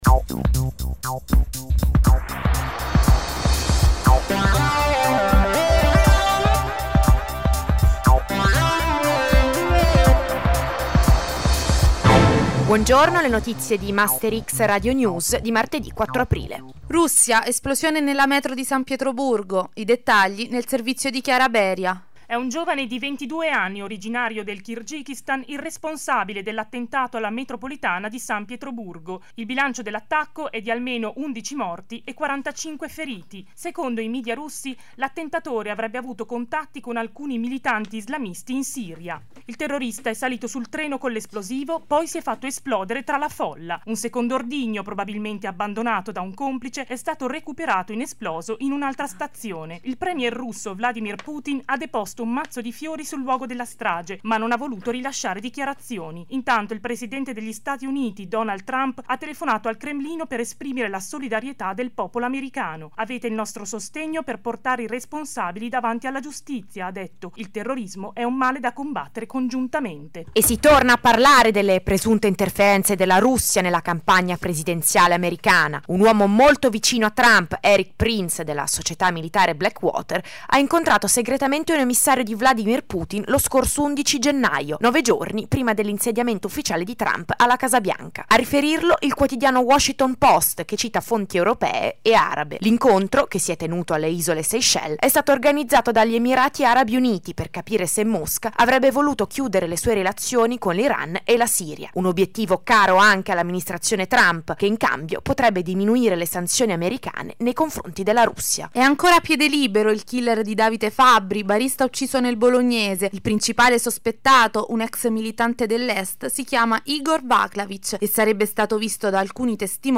GR LabIULM 4 aprile 2017